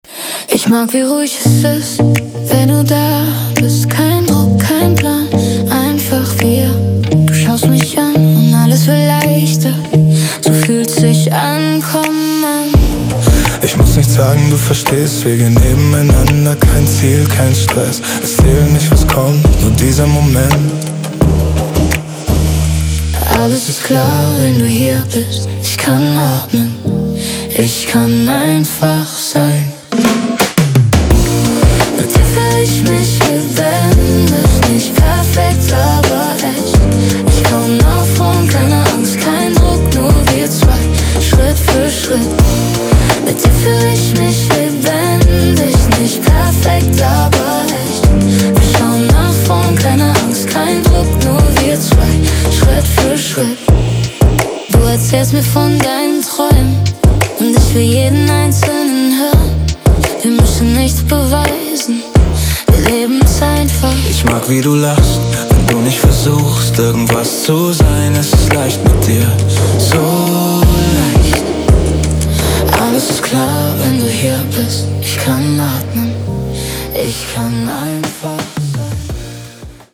Und wir lieben es, zu zweit zu singen.